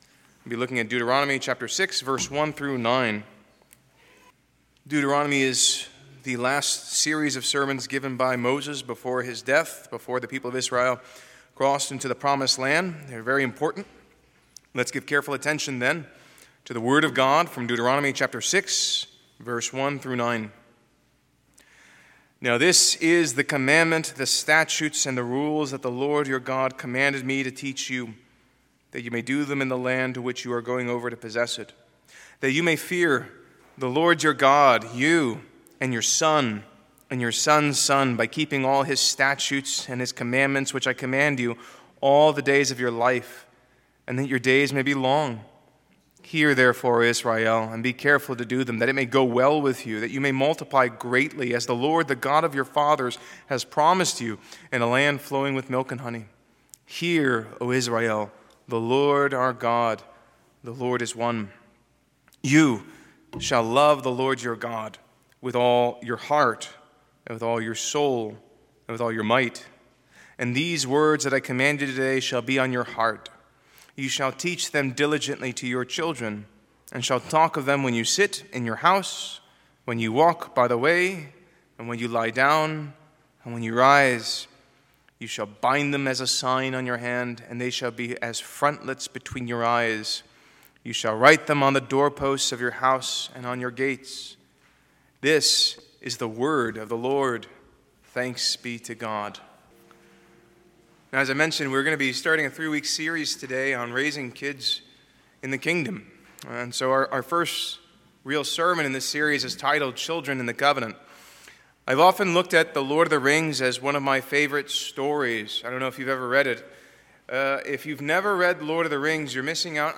Passage: Deuteronomy 6:1-9 Service Type: Morning Worship